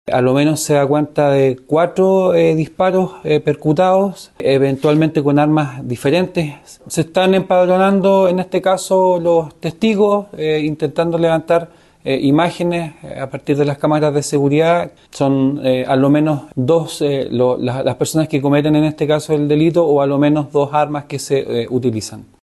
El fiscal Jonathan Muhlenbrock señaló que hay al menos cuatro disparos percutados en la escena del crimen.